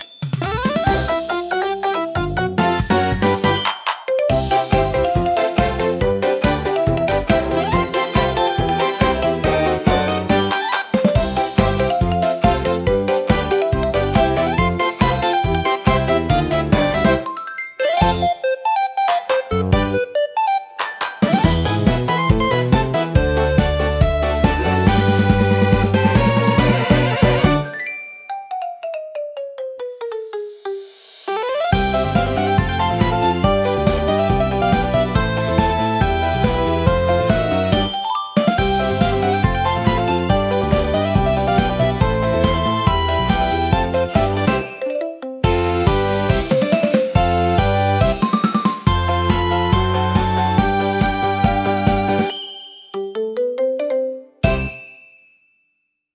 アクションは、明るくコミカルなシンセポップを特徴とするオリジナルジャンルです。
軽快なリズムと親しみやすいメロディーが、楽しく活発な雰囲気を作り出します。